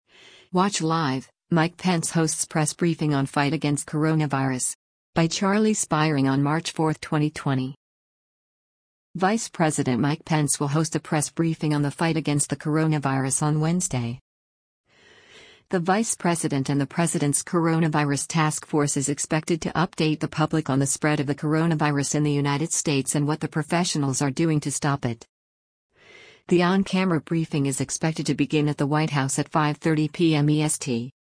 Watch Live: Mike Pence Hosts Press Briefing on Fight Against Coronavirus
The on-camera briefing is expected to begin at the White House at 5:30 p.m. EST.